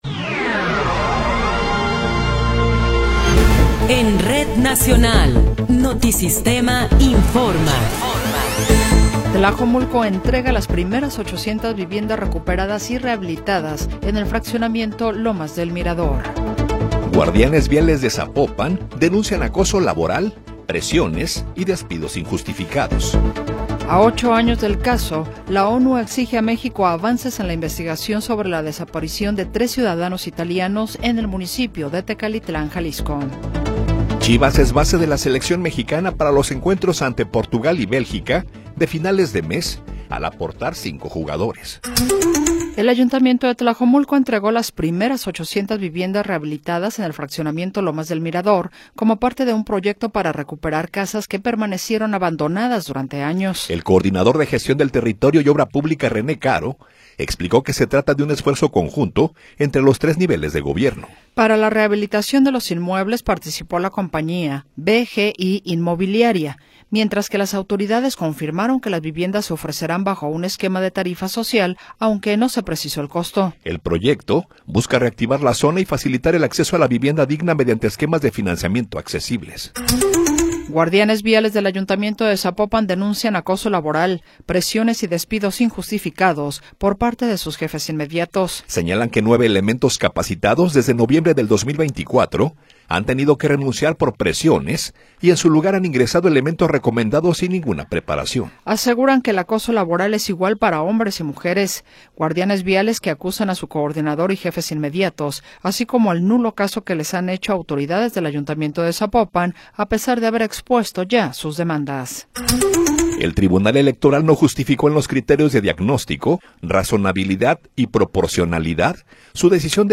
Noticiero 20 hrs. – 19 de Marzo de 2026
Resumen informativo Notisistema, la mejor y más completa información cada hora en la hora.